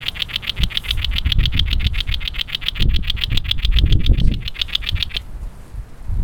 Brown Bush Warbler
warbler-brown-bush002-Locustella-luteoventris.mp3